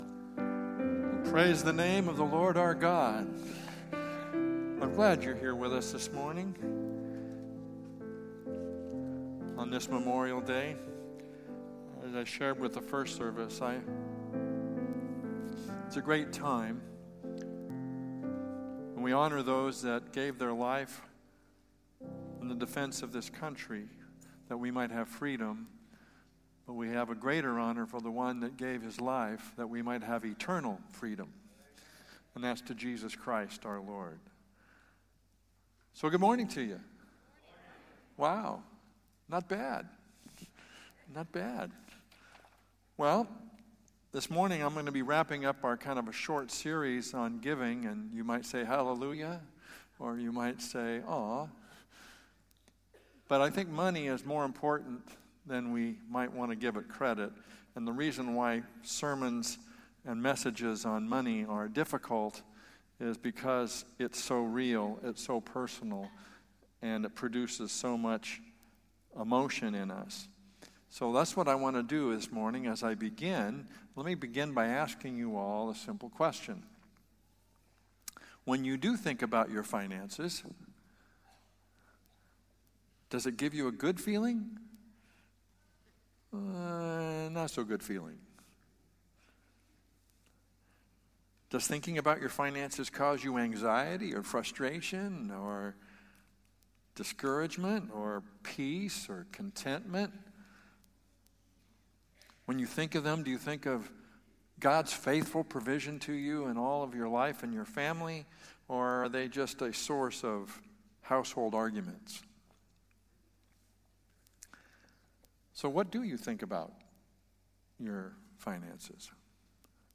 Valley Bible Church Sermons (VBC) - Hercules, CA